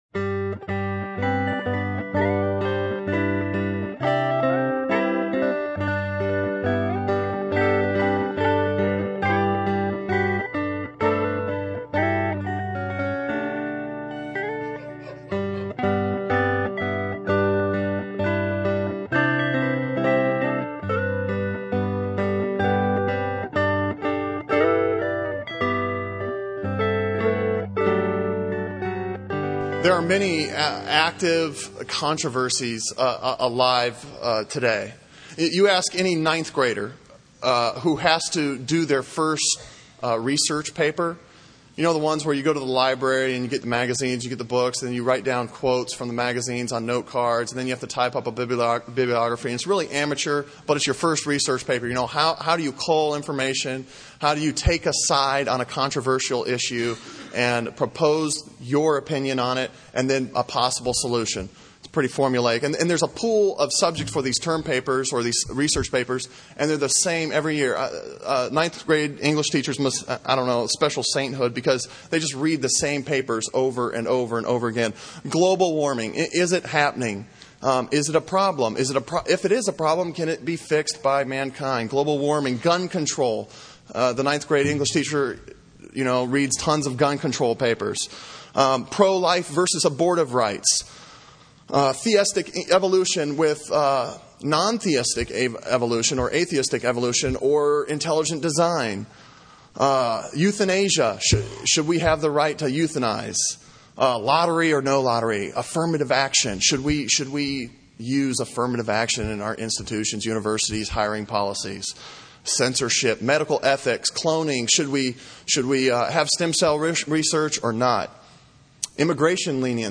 Sermon on Ephesians 3:1-6 from October 7